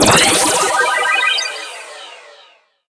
spawn_alternate.wav